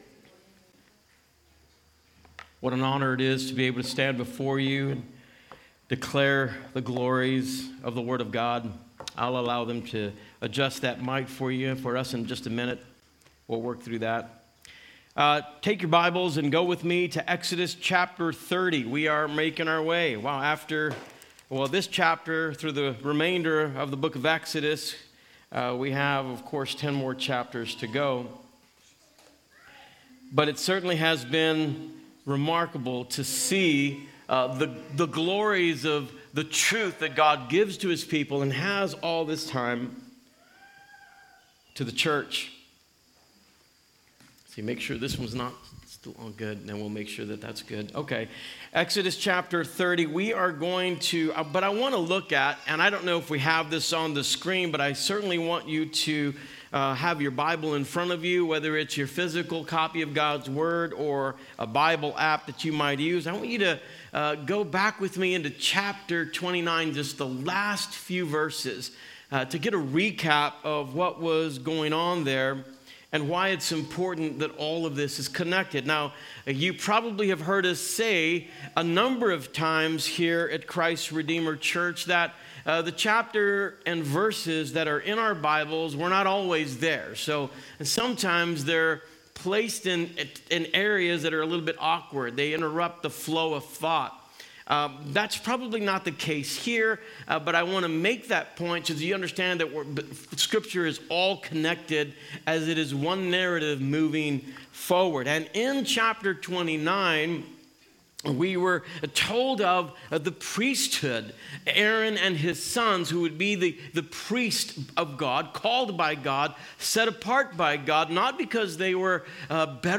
Sermons | Christ Redeemer Church